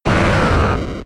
Cri d'Arbok K.O. dans Pokémon X et Y.